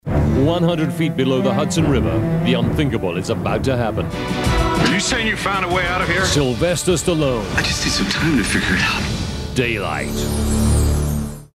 RP / Trans-Atlantic. Versatile - deep, smouldering, authoritative to warm, reassuring, ironic, Promos, Ads, Documentaries, Corporates.